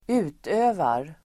Uttal: [²'u:tö:var]